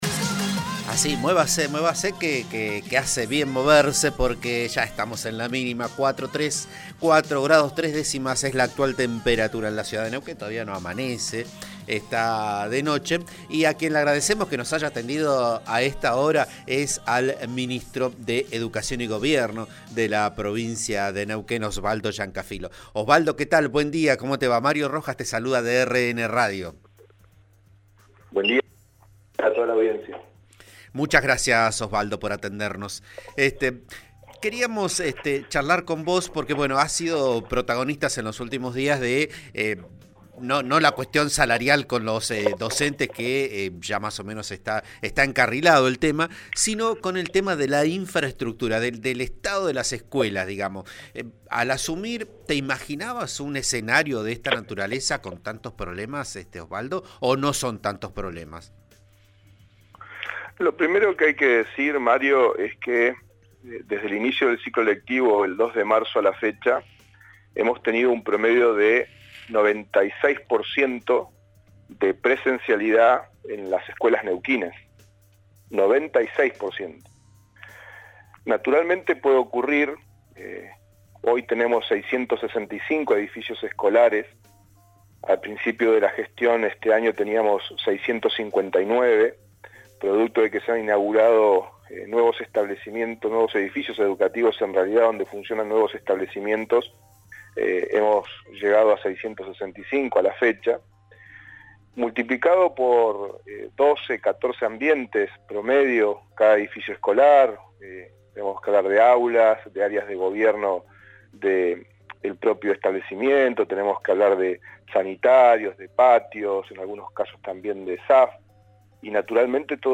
Esta mañana, en Vos A Diario por RN Radio, Llancafilo dimensionó el tema del mantenimiento escolar con el promedio de presencialidad de lo que va del año, que alcanzó el 96%.